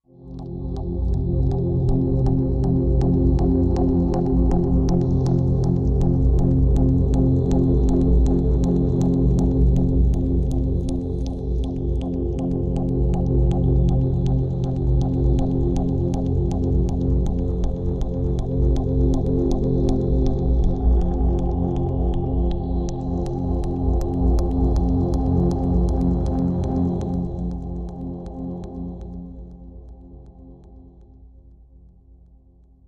Pulsing
Flotation Rhythmic Flow Ambient Electric Swirl